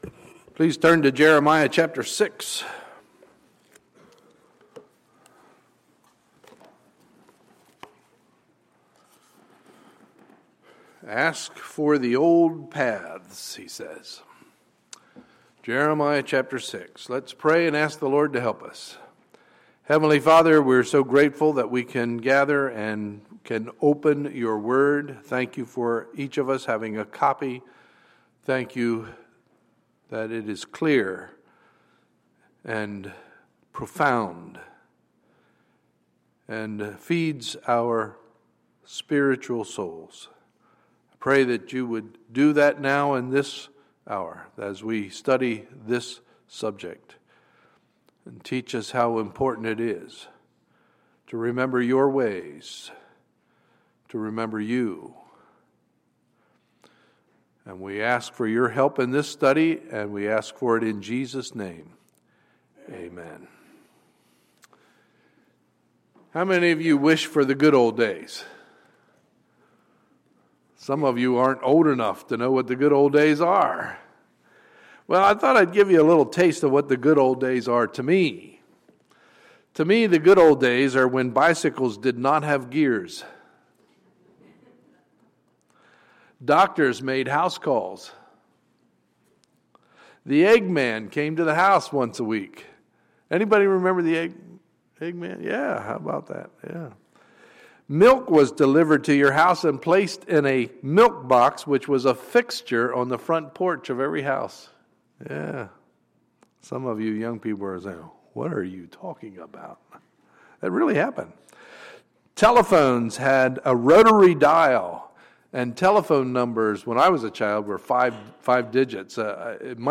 Sunday, February 15, 2015 – Sunday Morning Service